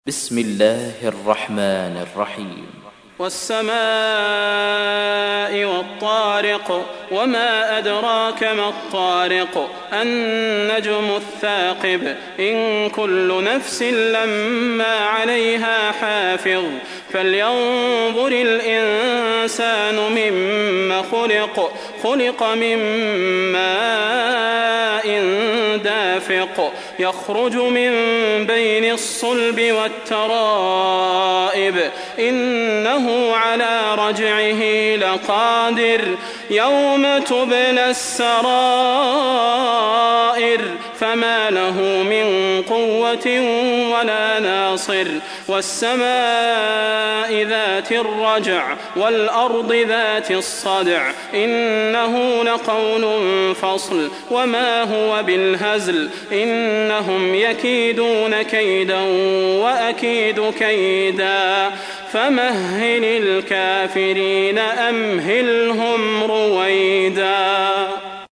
تحميل : 86. سورة الطارق / القارئ صلاح البدير / القرآن الكريم / موقع يا حسين